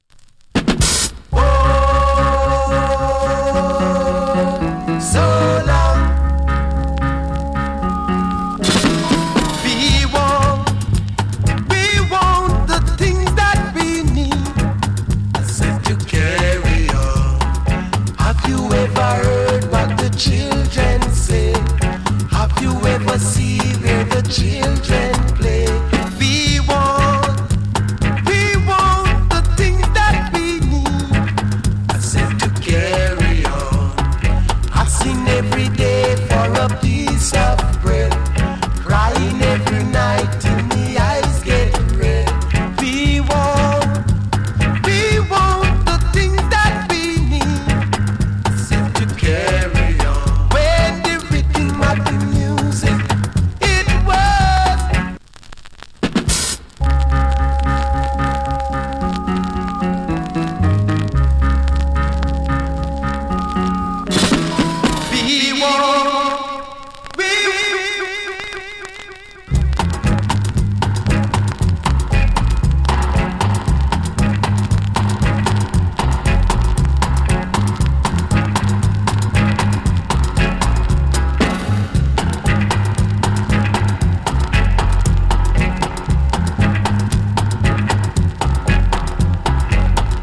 A：VG(OK) / B：VG(OK) LD. WOL. ＊スリキズ少々有り。チリ、パチノイズ有り。
DEEP STEPPER ROOTS !!